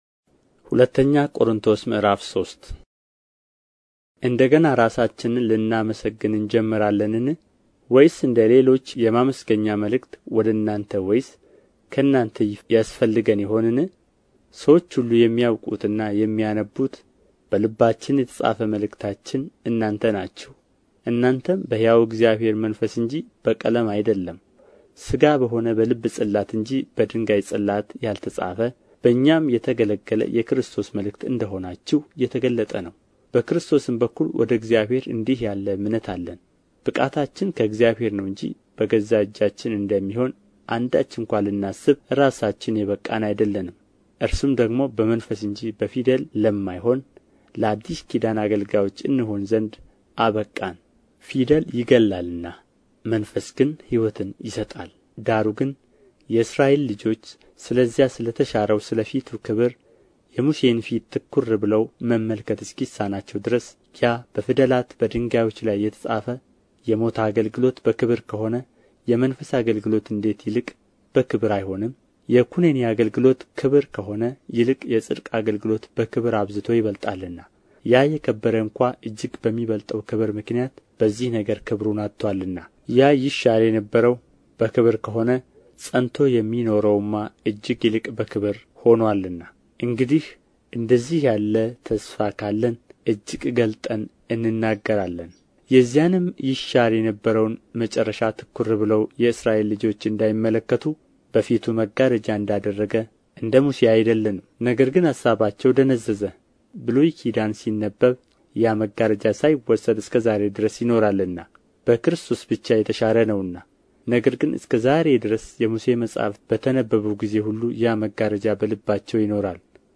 ምዕራፍ 3 ንባብ